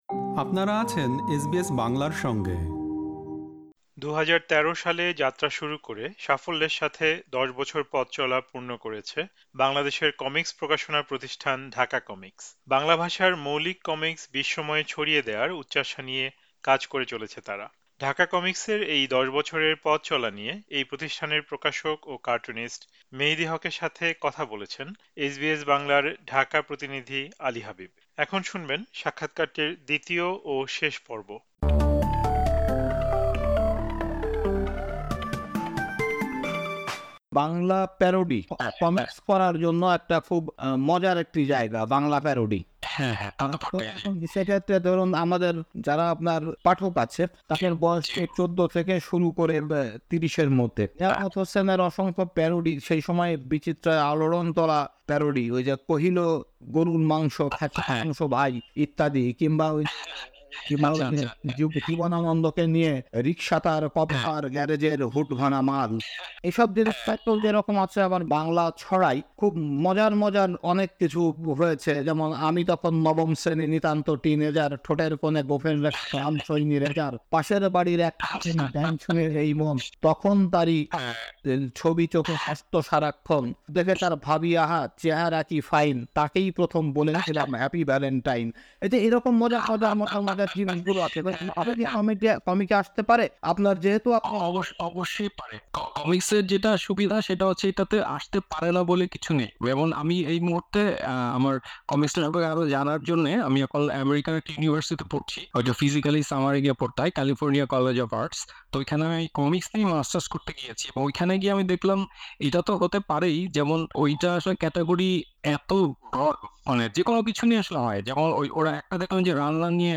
আজ শুনবেন সাক্ষাৎকারটির দ্বিতীয় ও শেষ পর্ব।